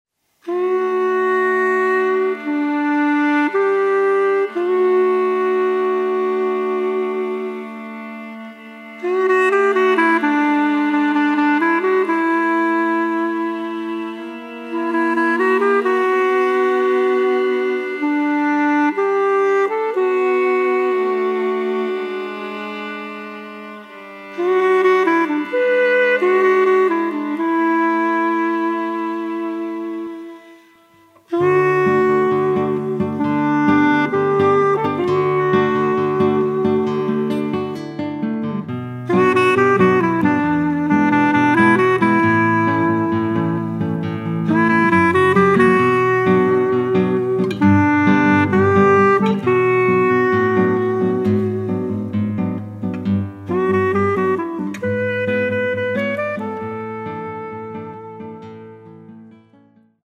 und Computersounds, eingängig und atmosphärisch dicht.